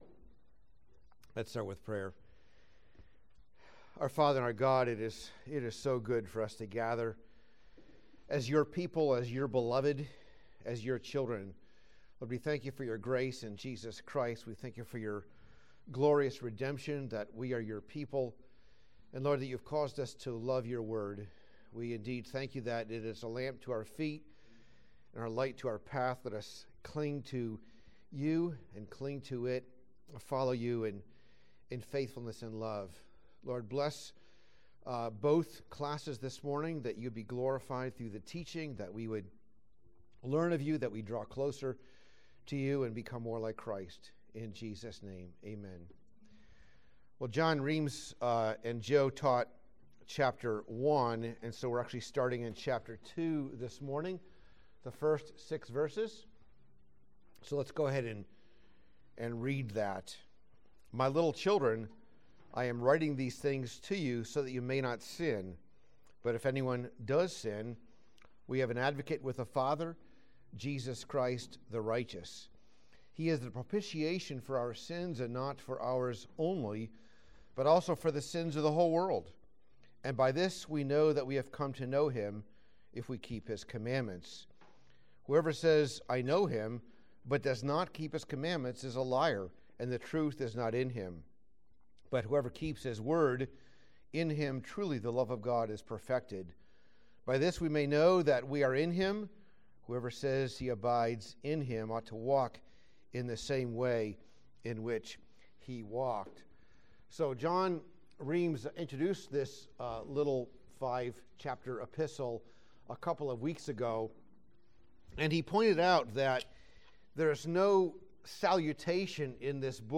Sermons and Adult Sunday School from Grace Presbyterian Church, Douglasville, Georgia